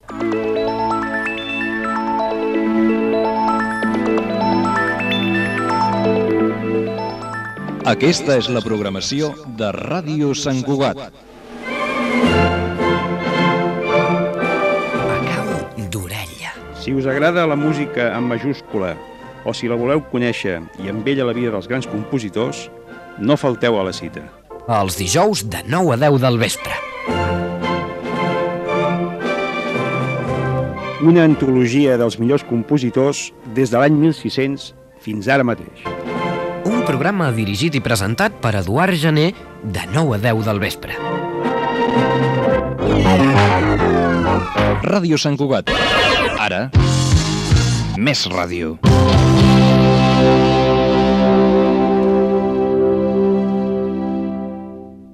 Indicatiu, promoció del programa "A cau d'orella" i indicatiu de l'emissora.
FM